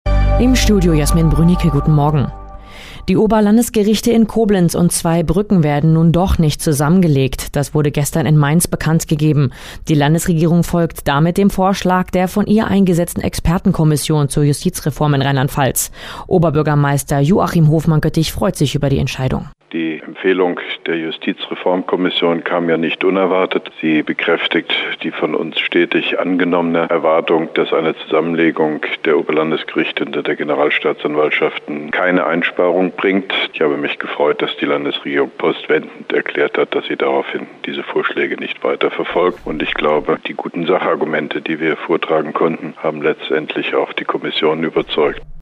Antenne Koblenz 98,0 Nachrichten, 28.03.2012, ab 8.30 Uhr stündliche Wiederholungen (Dauer 00:45 Minuten)
Mit Kurzstellungnahme von OB Hofmann-Göttig